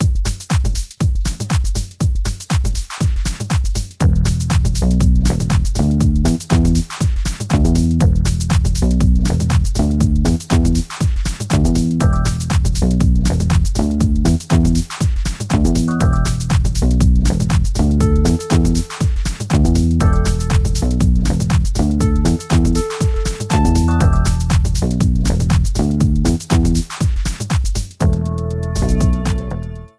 Credits theme